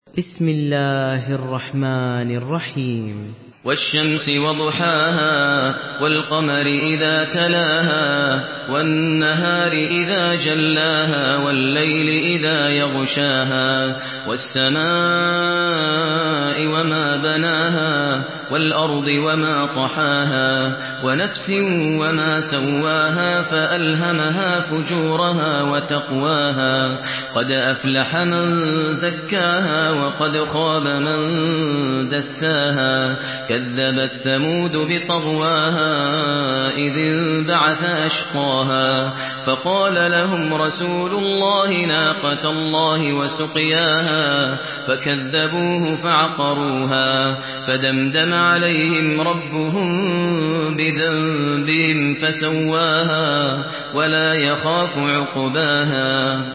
سورة الشمس MP3 بصوت ماهر المعيقلي برواية حفص
مرتل